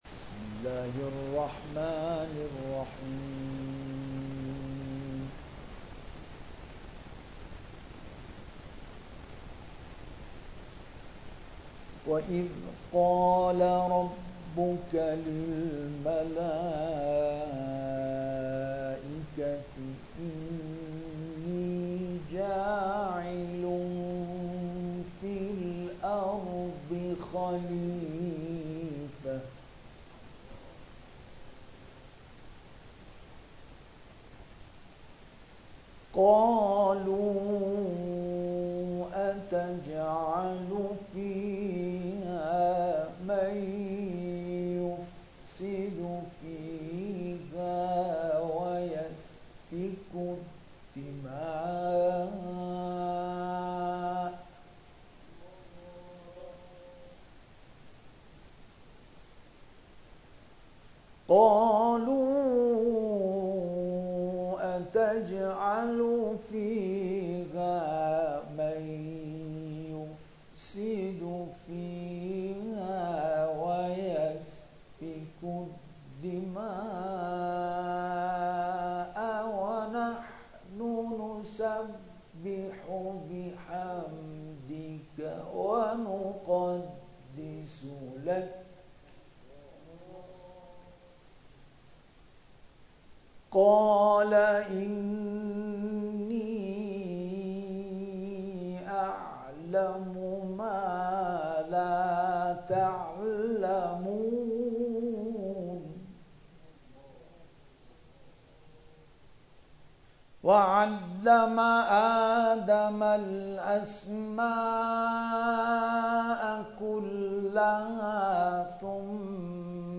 در ادامه تلاوت این قاری و داور ممتاز ارائه می‌شود.